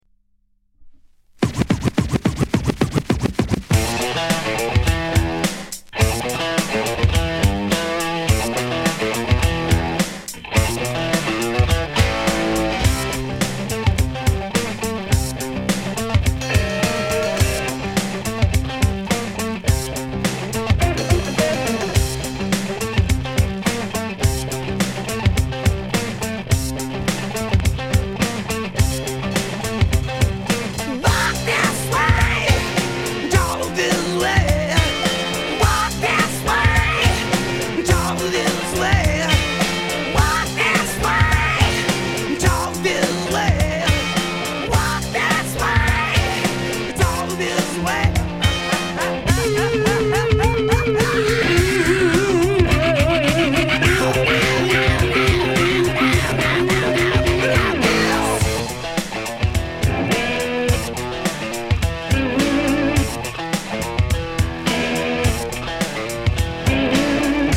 category Rap & Hip-Hop